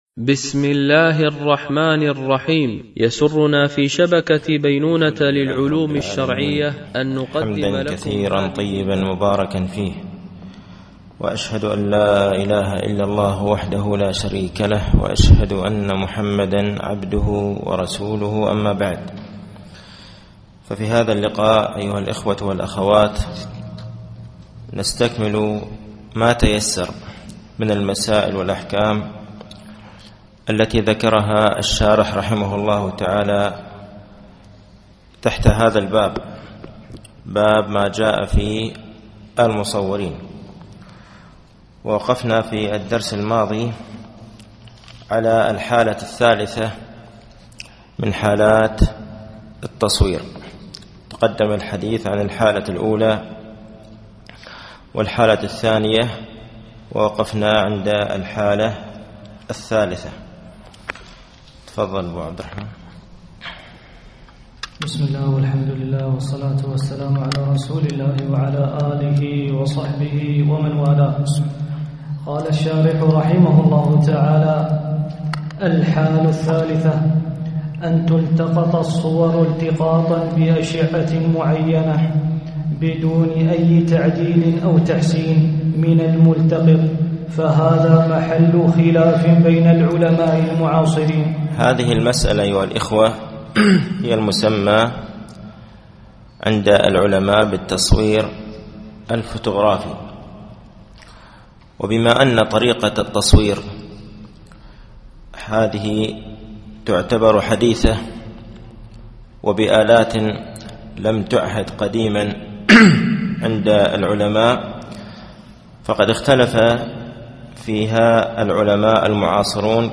التنسيق: MP3 Mono 22kHz 72Kbps (VBR)